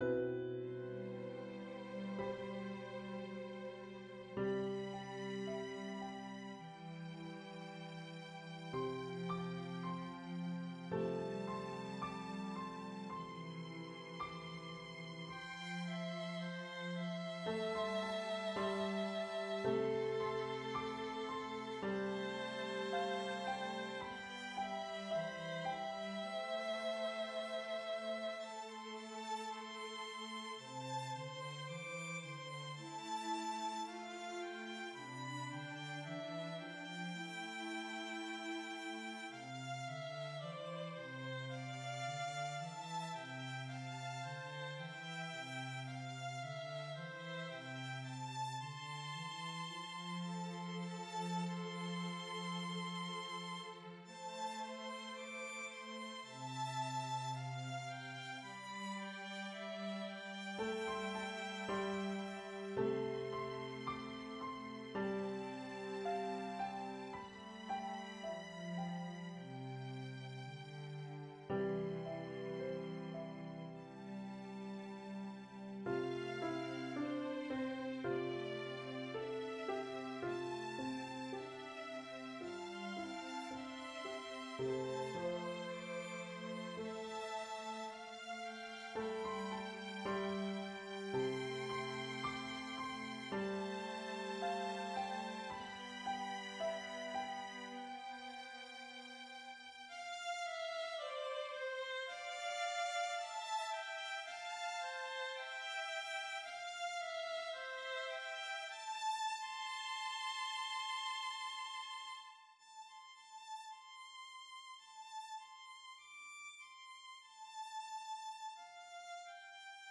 Symphonic Choir